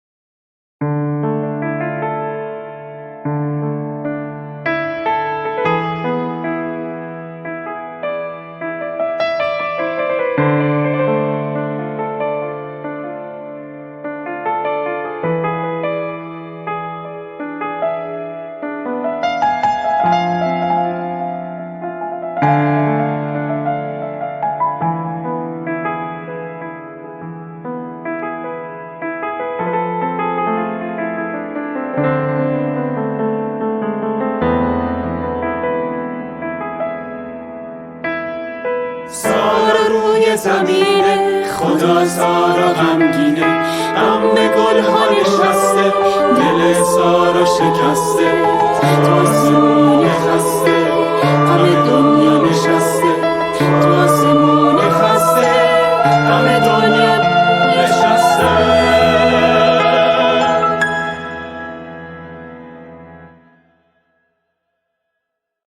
نمایش شنیداری و موسیقیایی
پیانو
‌ تنبک
چنگ
فلوت
کمانچه
سه تار
گروه همخوانی | کُر